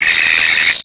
RbtStingeattack.wav